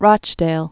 (rŏchdāl)